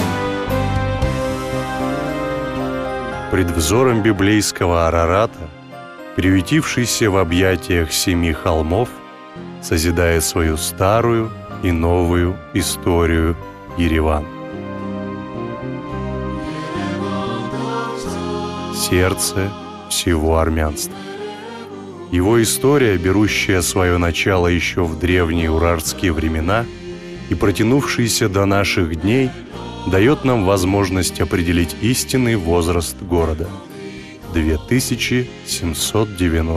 Мужской
русская озвучка